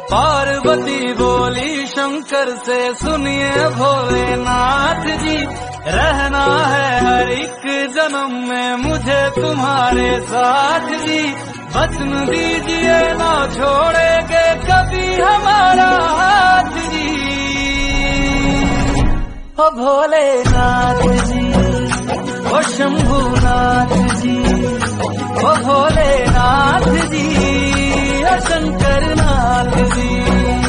Bhakti Ringtone